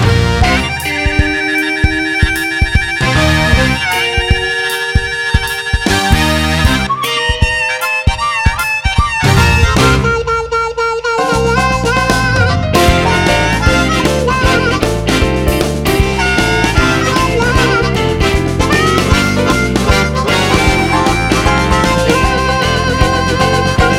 no Backing Vocals Soundtracks 2:48 Buy £1.50